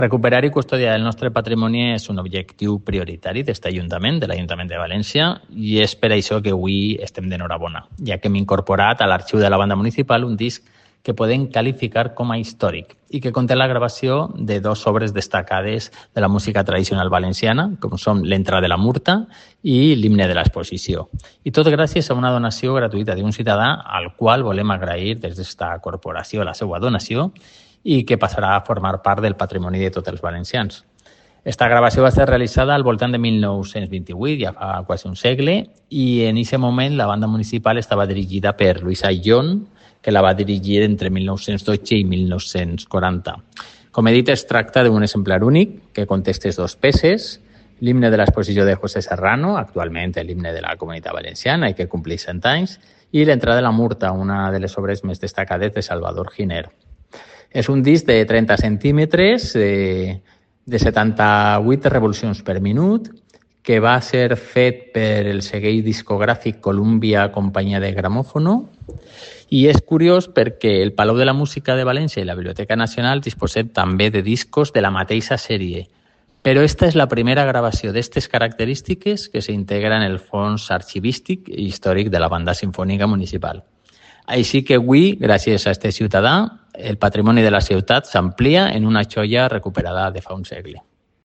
• La alcaldesa interviene en el acto de presentación